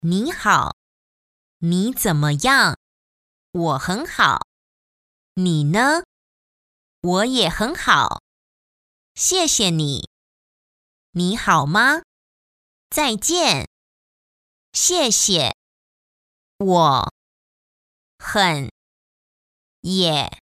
Young and energetic Mandarin Chinese and Taiwanese voice over talent.
Sprechprobe: Sonstiges (Muttersprache):